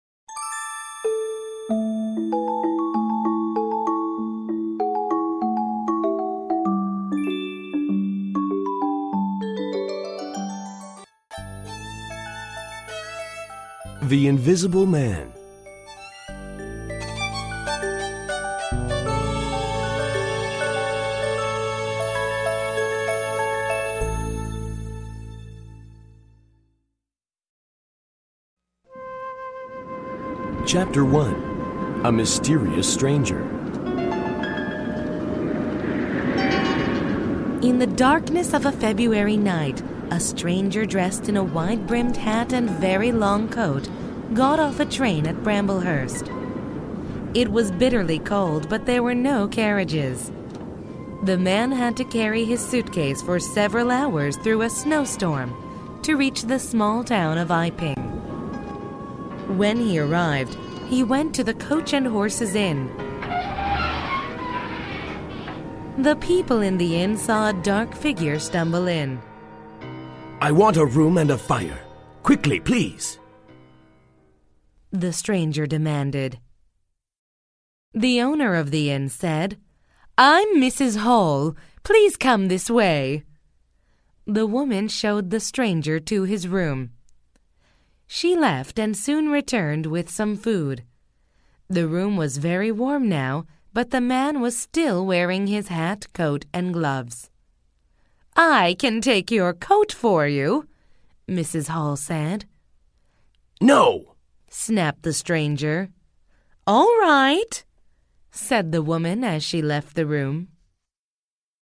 本書生動活潑的朗讀音檔，是由專業的美國播音員所錄製；故事是由以英文為母語的專業編輯，參照教育部公布的英文字彙改寫而成，對於所有學生將大有助益。
In the audio recording of the book, texts are vividly read by professional American actors.